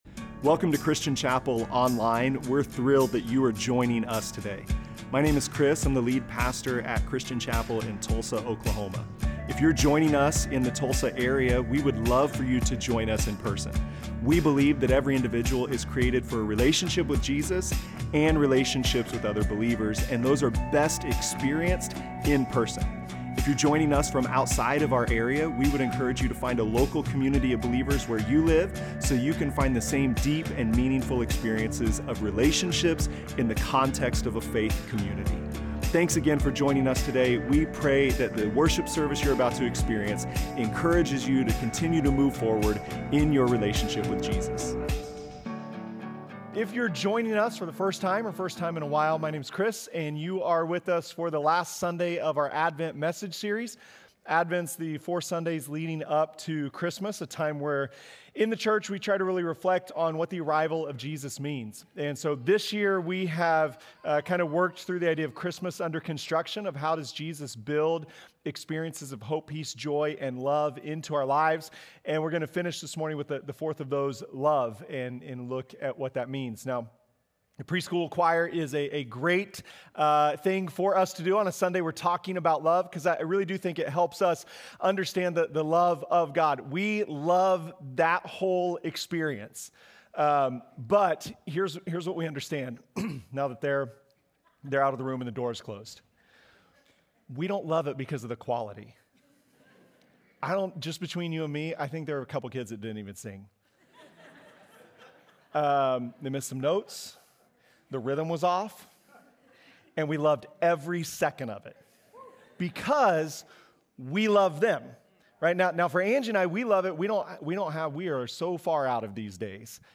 These are sermons and messages from Christian Chapel in Tulsa, Oklahoma.